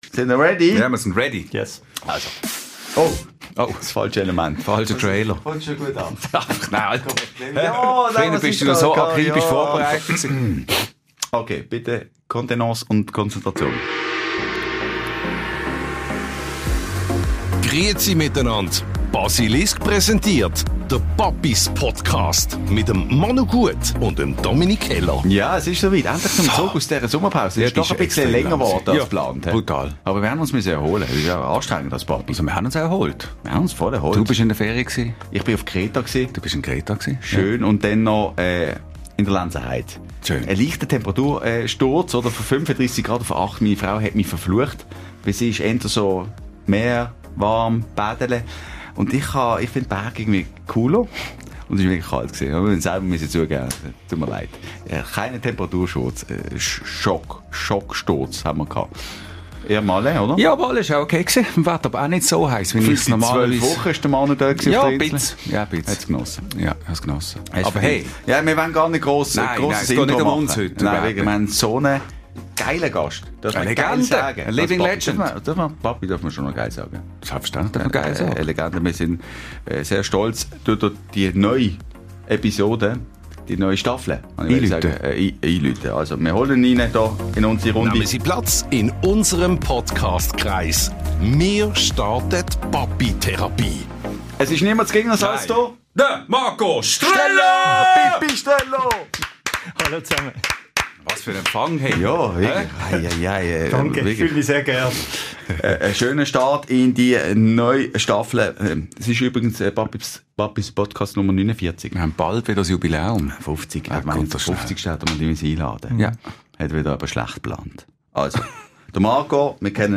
Ein Gespräch, das Mut macht, inspiriert und zeigt: Auch hinter grossen Stars steckt ein Mensch mit Sorgen, Zweifeln und Humor.